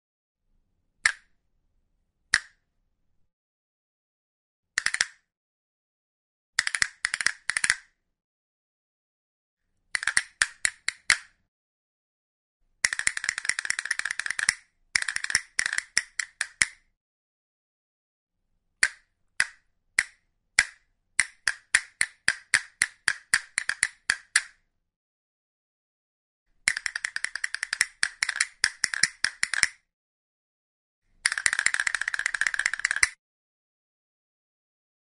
Traditional Spanish Instrument
Castanets
Sonido-de-Castanuelas.mp3